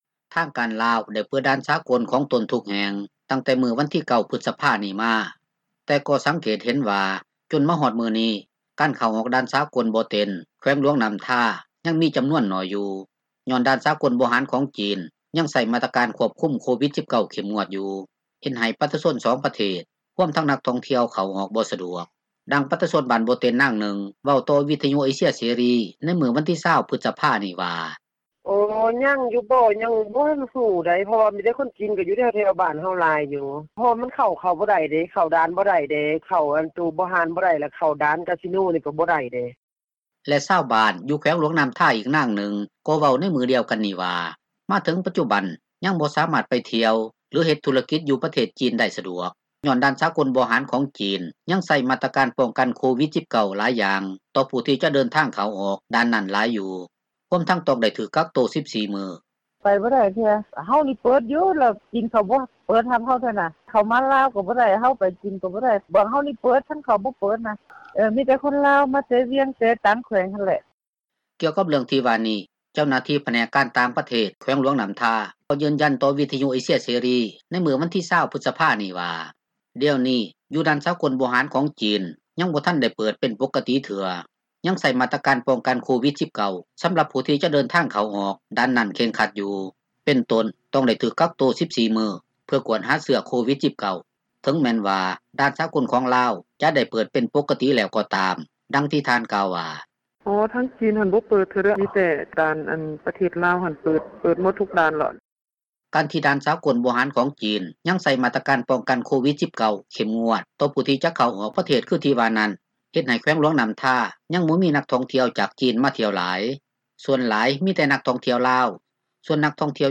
ດັ່ງປະຊາຊົນບ້ານບໍ່ເຕັນນາງນຶ່ງ ເວົ້າຕໍ່ວິທຍຸເອເຊັຽເສຣີໃນມື້ວັນທີ 20 ພຶສພານີ້ວ່າ: